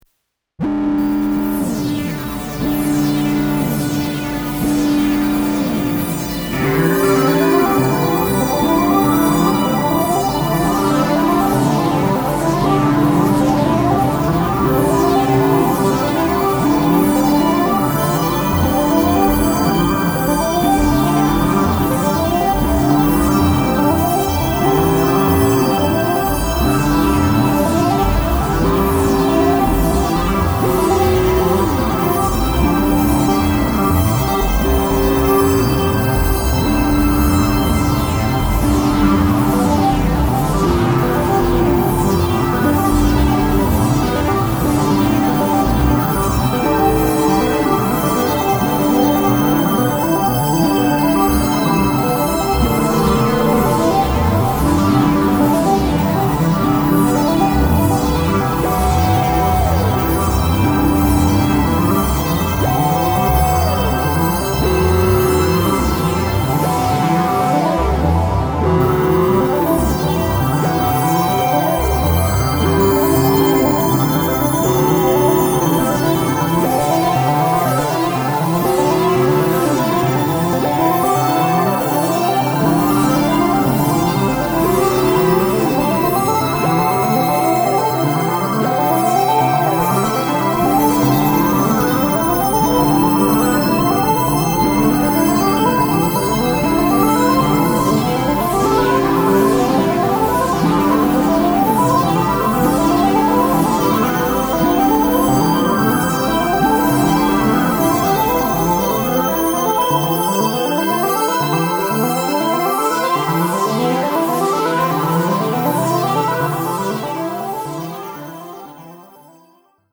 Essentially, this piece started off as three seperately played live arpeggiations – one focusing on a sort of standard synth sound, one “playing” a preset not unlike a military ship’s warning klaxon, and one of a glistening crescendo loaded with piercingly high frequencies.
The second piece, Insanity, mixed all 3 of them together… simultaneously. The result is an absolute sonic cacophony of insane noise – which, surprisingly enough, I actually like listening to (at least until it makes my ears start bleeding and I have to reach for the volume off control in desperation).
arpex-fullmix.mp3